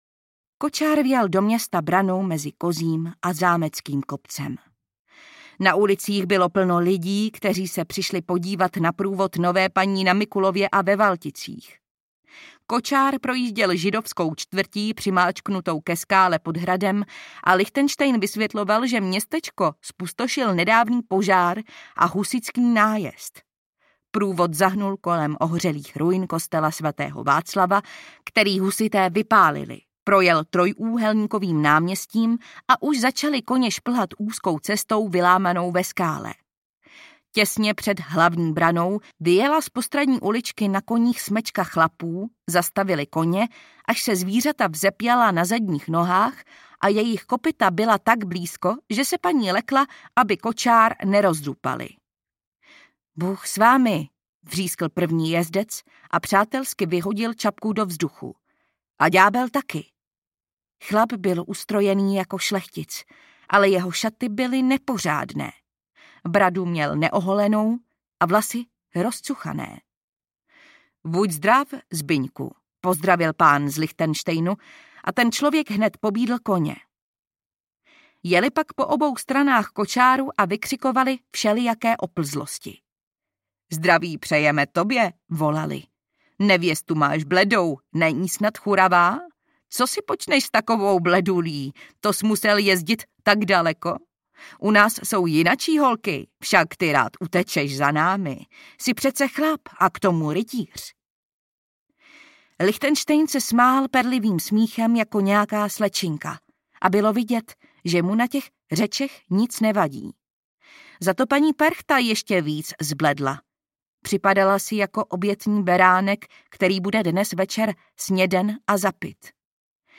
Perchta z Rožmberka aneb Bílá paní audiokniha
Ukázka z knihy
perchta-z-rozmberka-aneb-bila-pani-audiokniha